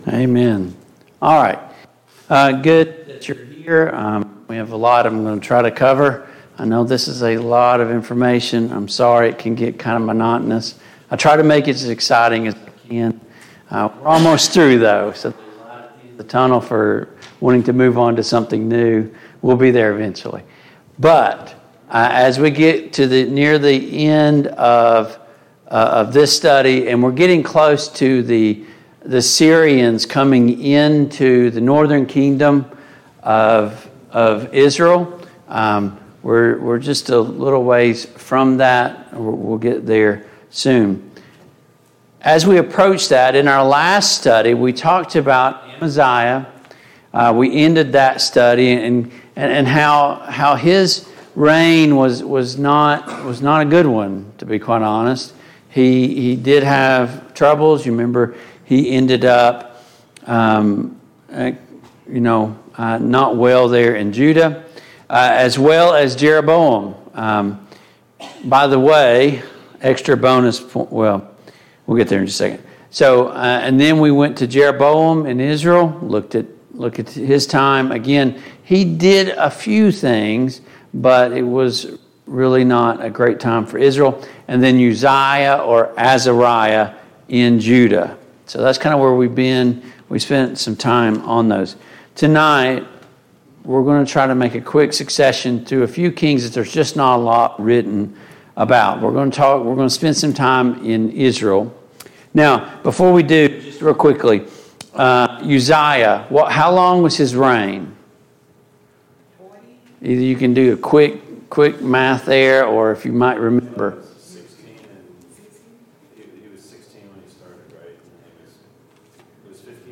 Passage: 2 Kings 15 Service Type: Mid-Week Bible Study Download Files Notes « Deliverance from the Lord 2.